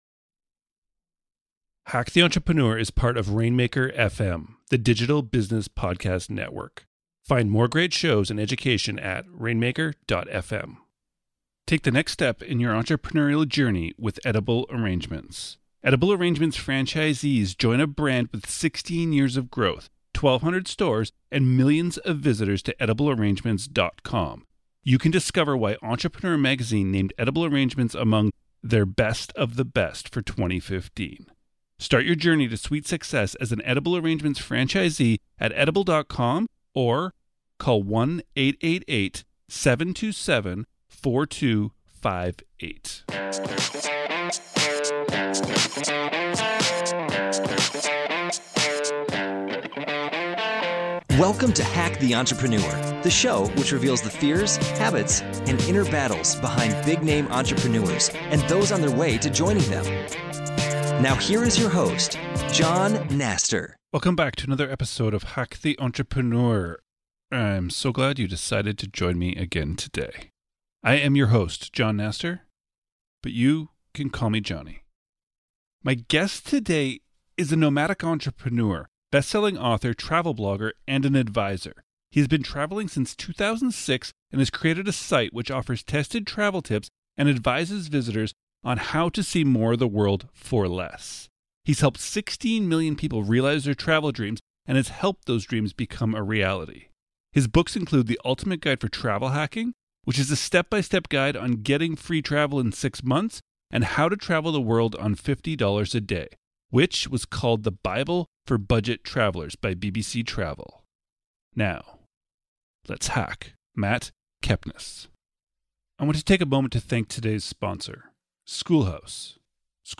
My guest today is a nomadic entrepreneur, bestselling author, travel blogger and adviser.